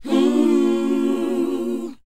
WHOA G#B.wav